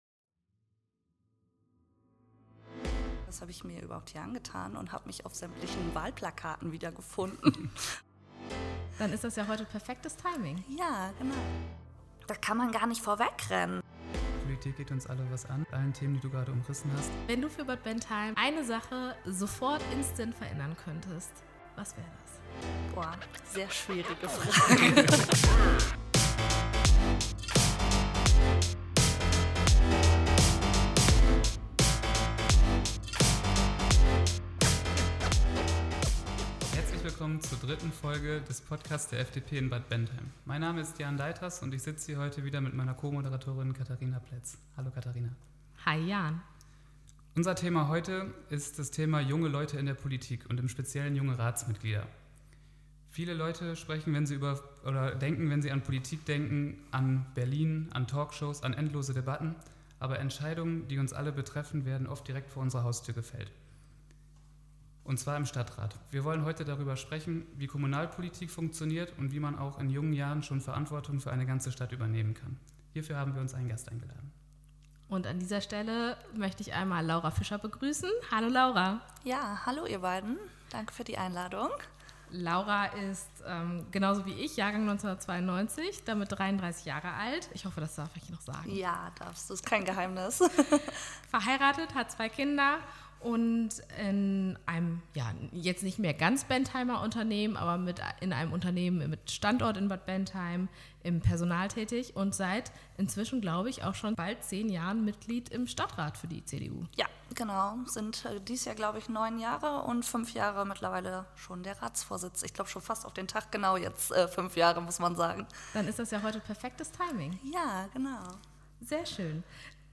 Wir sprechen mit Laura Fischer darüber, was die Ratsvorsitzende des Bentheimer Stadtrats macht und wie es ist, als junger Mensch in der Kommunalpolitik für seinen Ort Verantwortung zu übernehmen.